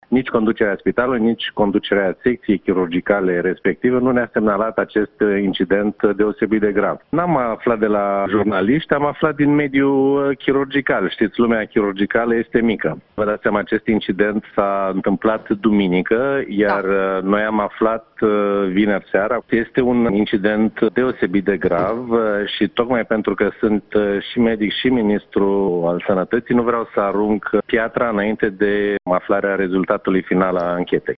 În schimb, ministrul Victor Costache susţine că s-a dorit muşamalizarea acestui caz: